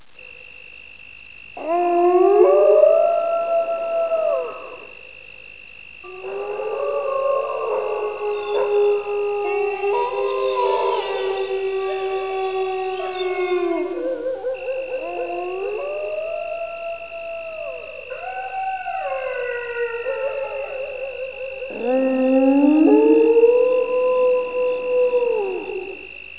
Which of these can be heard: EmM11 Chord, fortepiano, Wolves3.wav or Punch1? Wolves3.wav